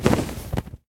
dragon_wings1.ogg